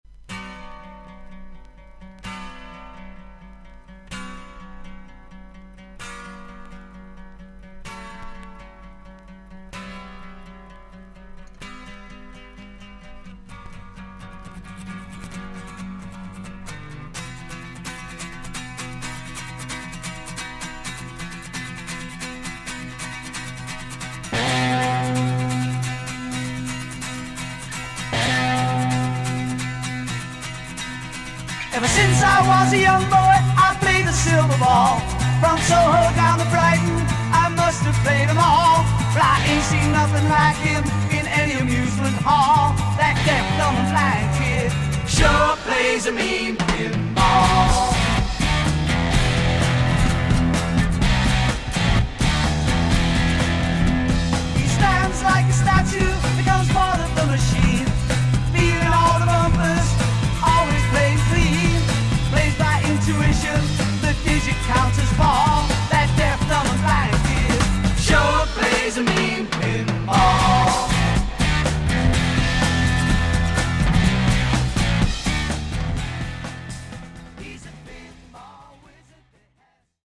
ロックオペラ
VG++〜VG+ 少々軽いパチノイズの箇所あり。クリアな音です。